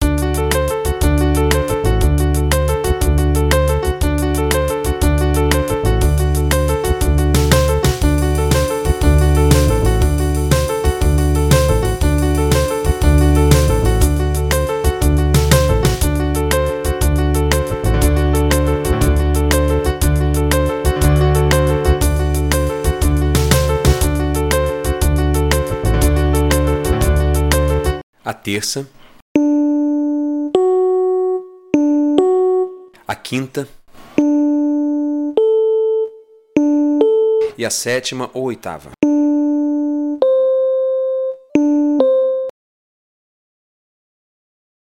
Você vai ouvir o acorde tocado por uma banda e, em seguida, nó vamos desmembrá-lo nas notas que o compôe.